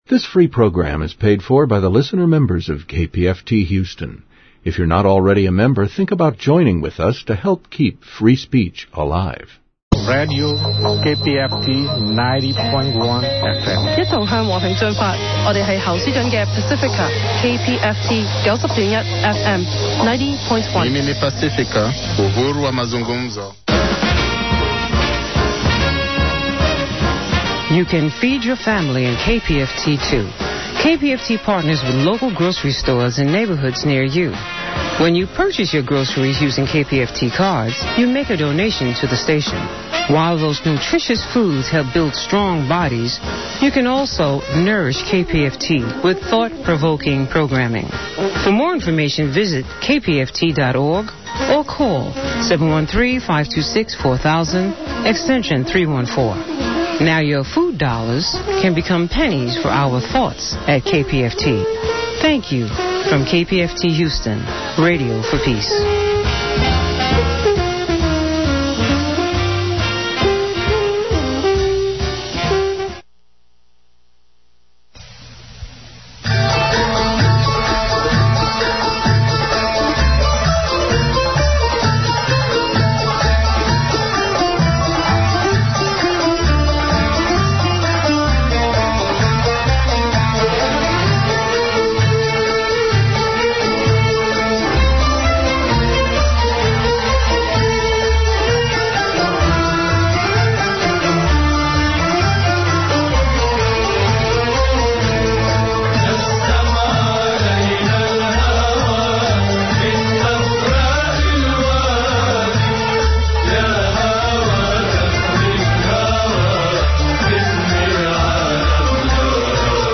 Arab and Muslim Perspectives on the Iraq Study Group Report: A panel discussion on the Iraq Study Group Report which took place at the National Press Club in Washington, D.C. on December 12, 2006.